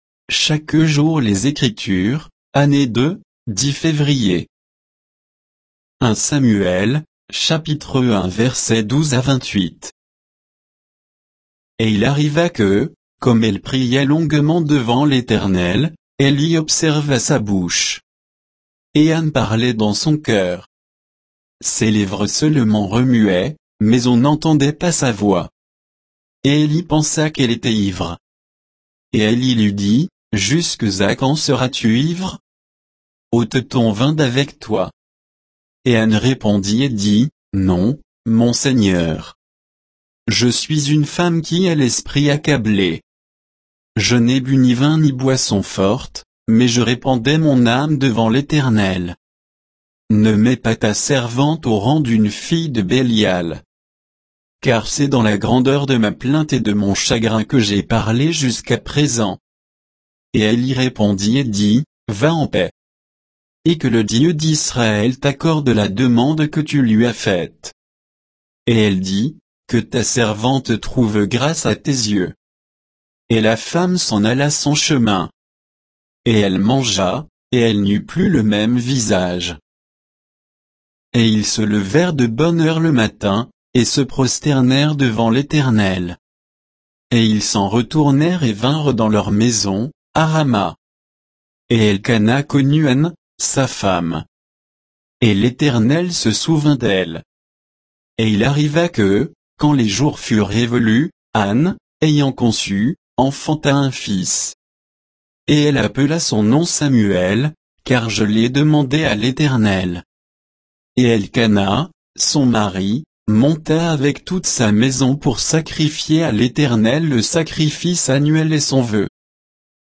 Méditation quoditienne de Chaque jour les Écritures sur 1 Samuel 1